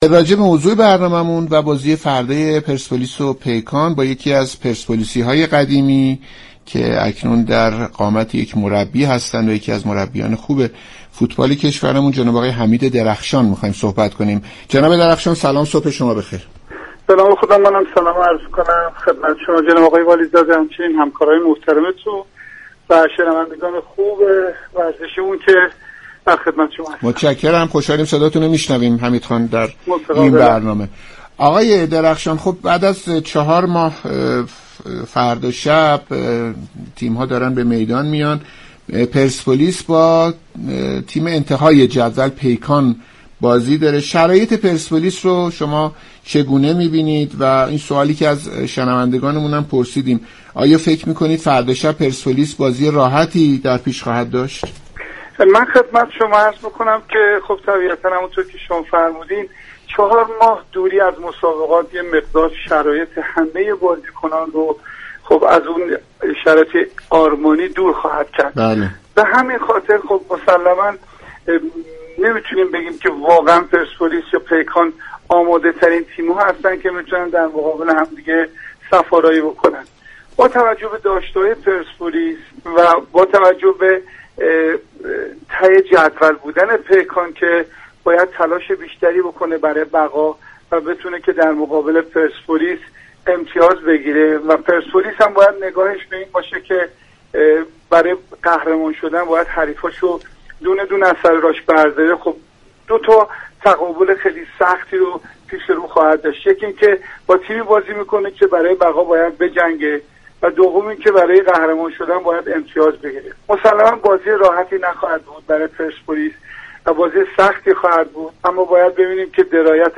برنامه «صبح و ورزش» شنبه 7 تیر در گفتگو با حمید درخشان، پیشكسوت تیم پرسپولیس و مربی فوتبال به تشریح وضعیت این تیم برای رقابت روز یكشنبه با پیكان پرداخت.